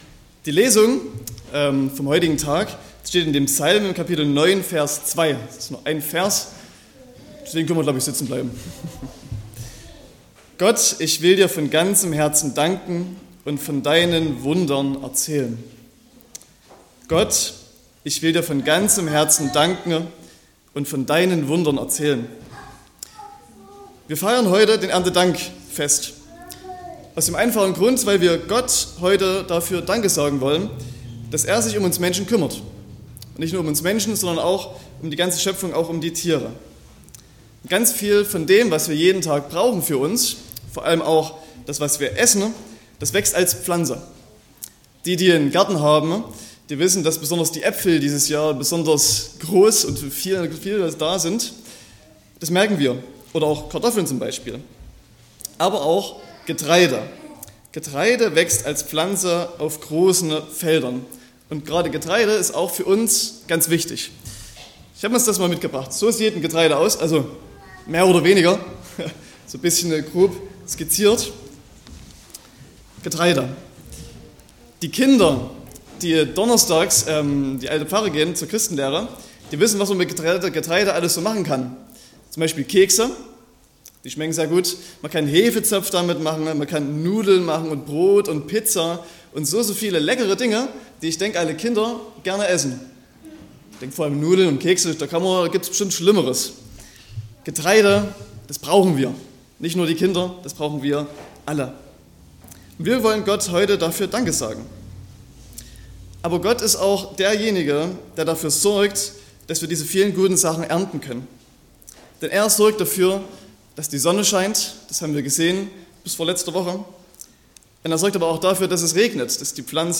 28.09.2025 – Gottesdienst zum Erntedankfest
Predigt (Audio): 2025-09-28_Von_ganzem_Herzen_danken.mp3 (6,4 MB)